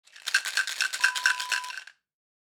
Pill container sound effect .wav #2
Description: The sound of a pill container being shaken
Properties: 48.000 kHz 24-bit Stereo
A beep sound is embedded in the audio preview file but it is not present in the high resolution downloadable wav file.
Keywords: plastic, pills, rattle, shake, shaking
pill-container-preview-2.mp3